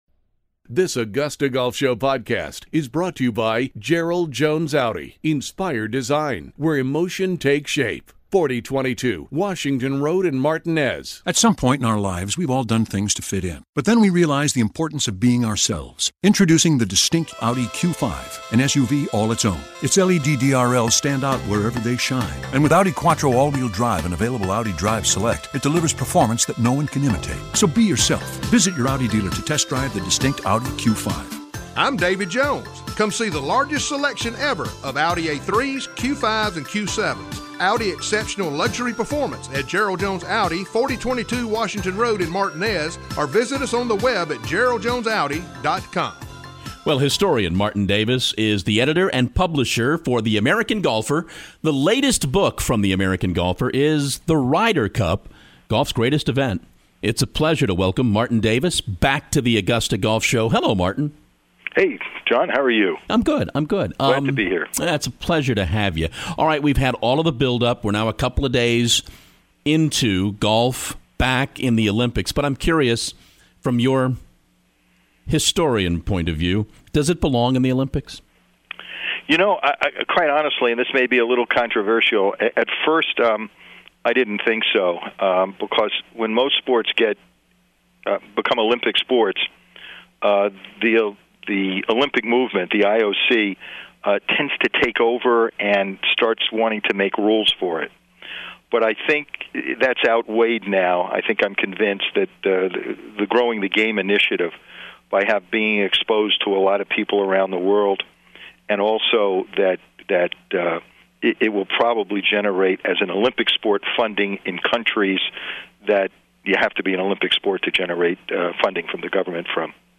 The Augusta Golf Show Interview